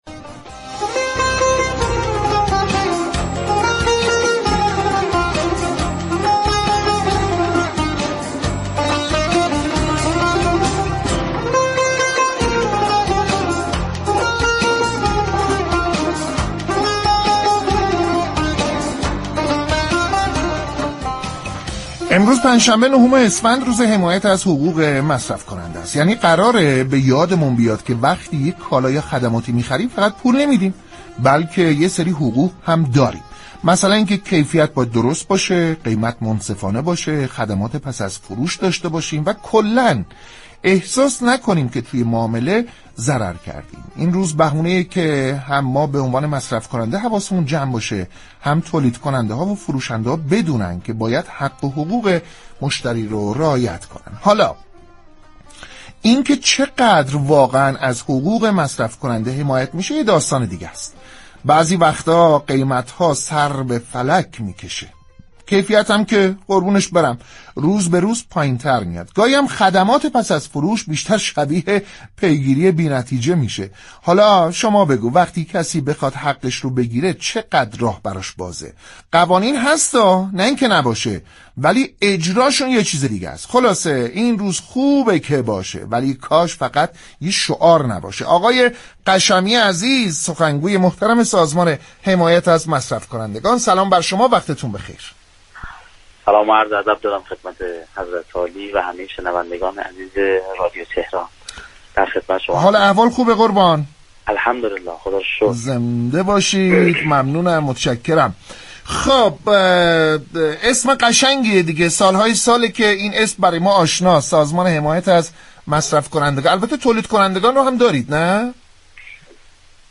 در گفت و گو با رادیو تهران